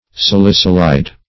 salicylide - definition of salicylide - synonyms, pronunciation, spelling from Free Dictionary
salicylide.mp3